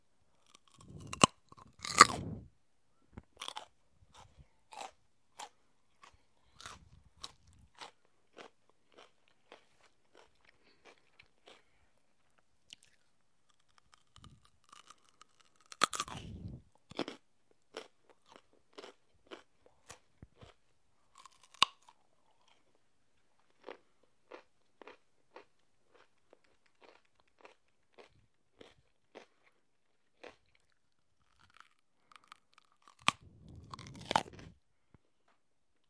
咀嚼
描述：咬和嚼胡萝卜。
Tag: 紧缩 咀嚼 胡萝卜